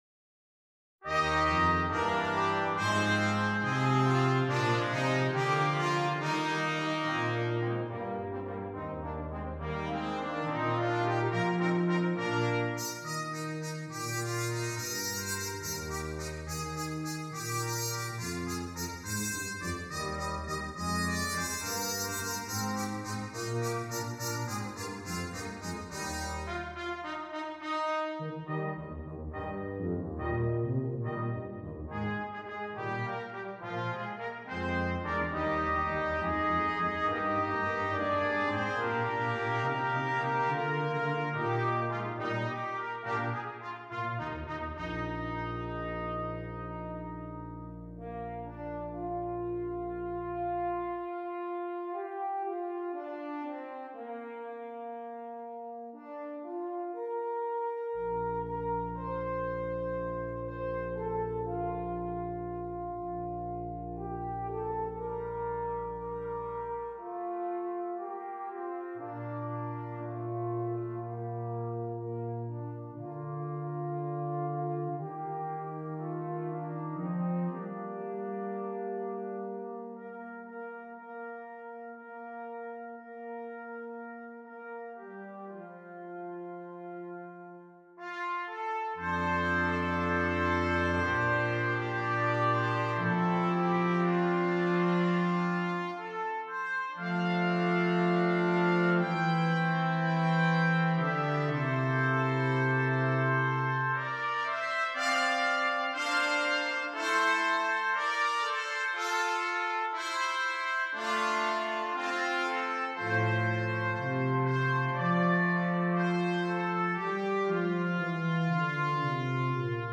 Brass Quintet
Canadian Folk Song